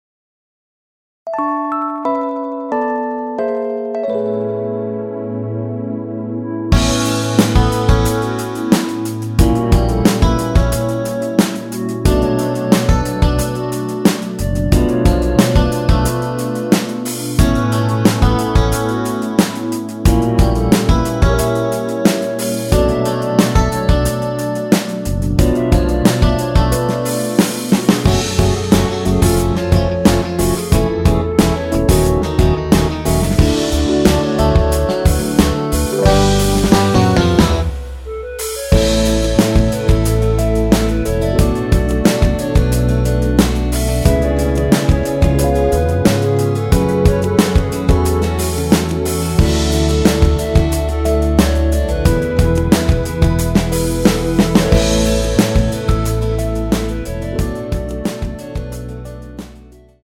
원키에서(-2)내린 멜로디 포함된 MR입니다.
멜로디 MR이란
앞부분30초, 뒷부분30초씩 편집해서 올려 드리고 있습니다.
중간에 음이 끈어지고 다시 나오는 이유는